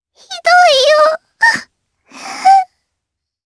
Lavril-Vox_Sad_jp_b.wav